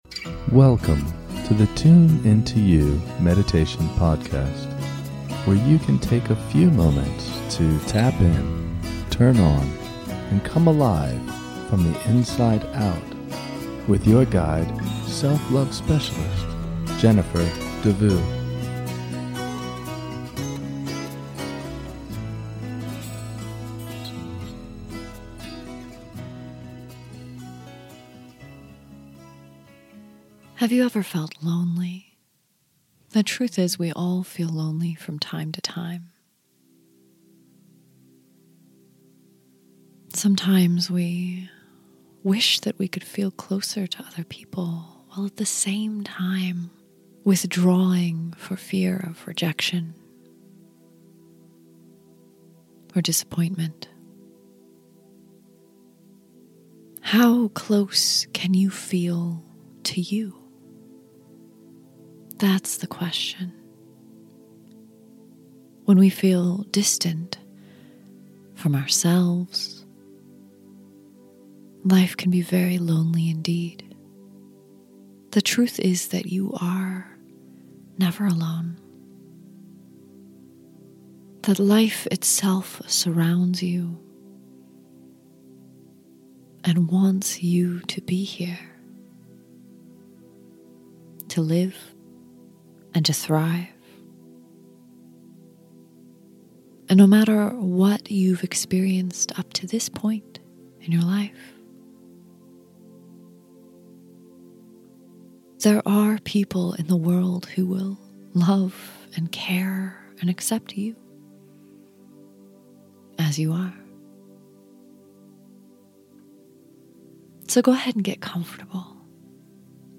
In this short guided meditation, we will explore your inner space as you connect more deeply to yourself and find the peace buried within. This is a wonderful meditation to come back to again and again, anytime you feel a little disconnected or long to uncover more of you.